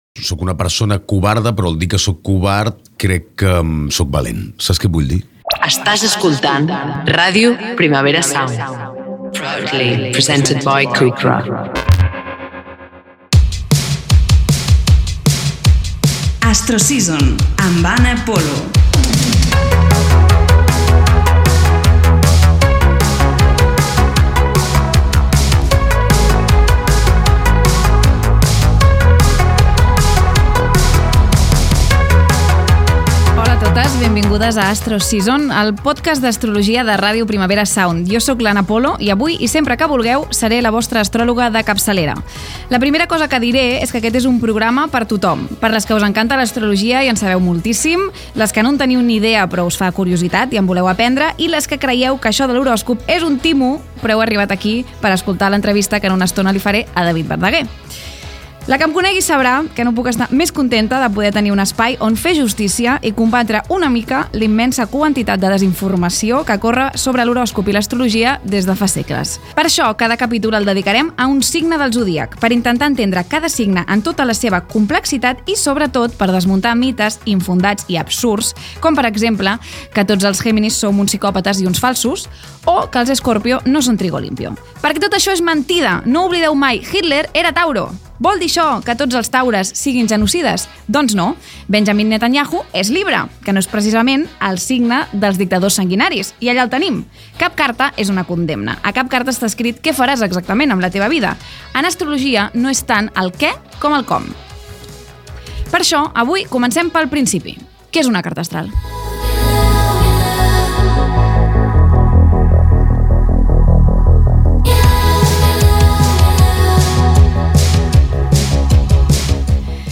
d4e848dbfd93eabfa3a419afcdbbdb98ed9600b3.mp3 Títol Ràdio Primavera Sound Emissora Ràdio Primavera Sound Titularitat Tercer sector Tercer sector Altres Nom programa Astro Season Descripció Primera edició del programa. Indicatiu de la ràdio, publicitat, careta, presentació, objectiu del programa, què és una carta astral, el signe de balança, entrevista a l'actor David Verdaguer amb qui es repassa la seva carta astral Gènere radiofònic Entreteniment